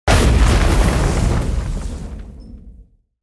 دانلود صدای کامیون 5 از ساعد نیوز با لینک مستقیم و کیفیت بالا
جلوه های صوتی